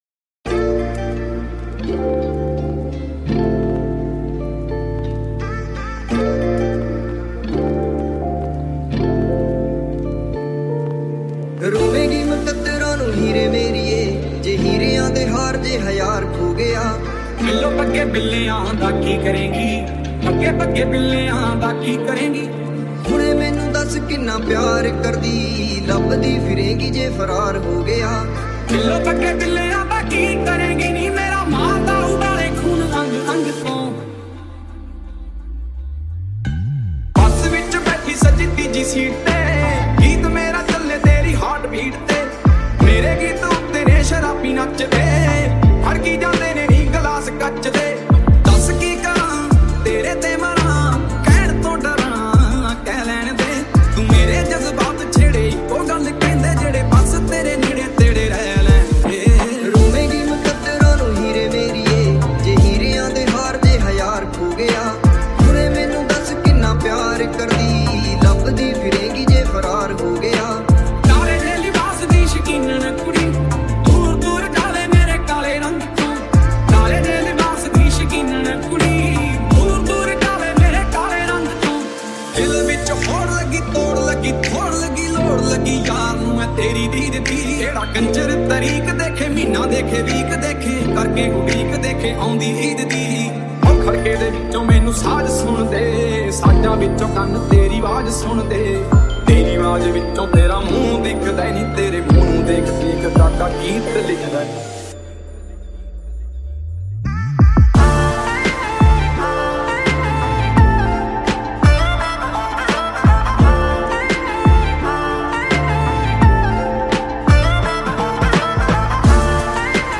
High quality Sri Lankan remix MP3 (4.1).
high quality remix